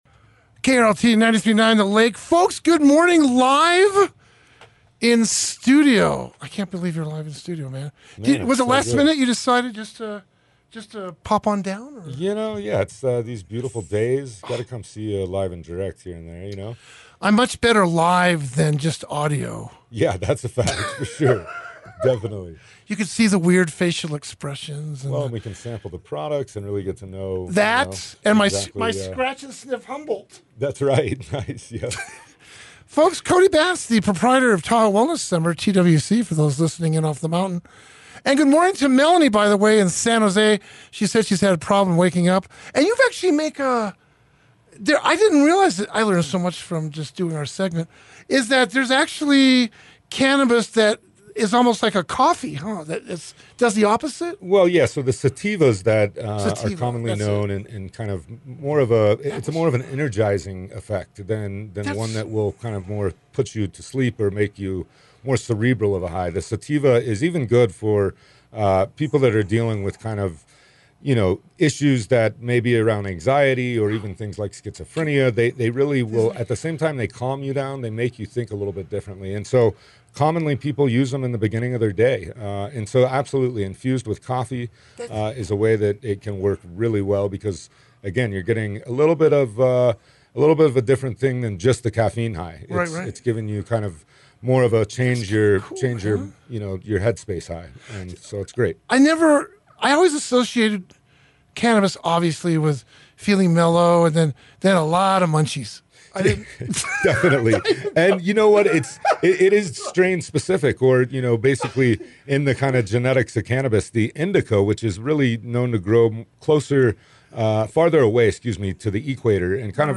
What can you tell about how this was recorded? Live in studio.